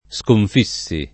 Skonf&SSi]; part. pass. sconfitto [Skonf&tto] — non sconfingere — cfr. scalfire